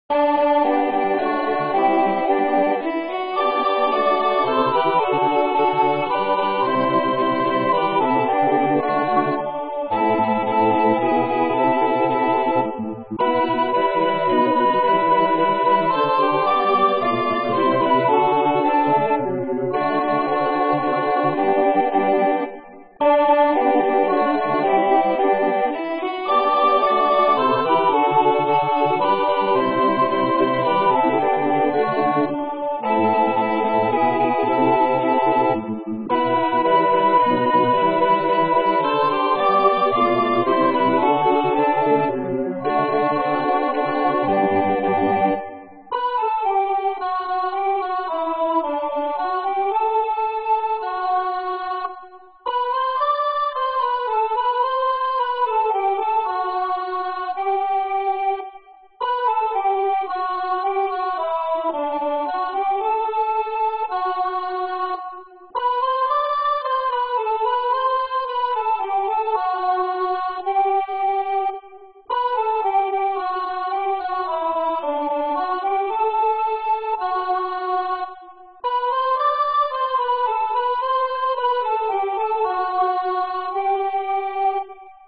Makundi Nyimbo: Anthem | Zaburi